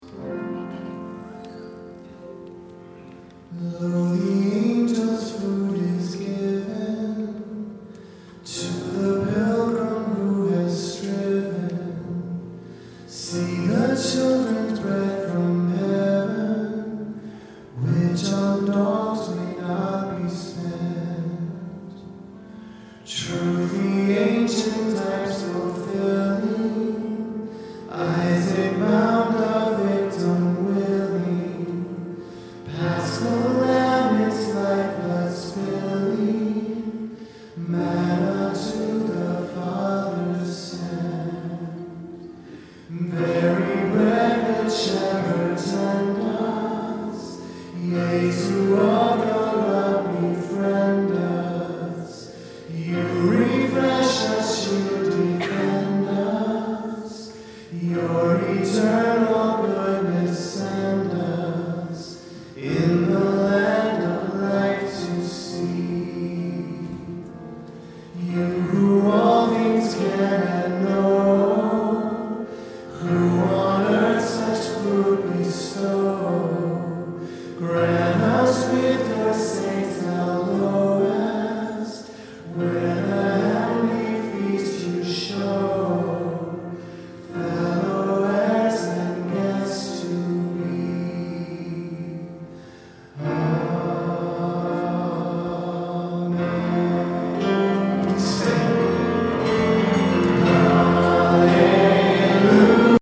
Voicing: Unison; Assembly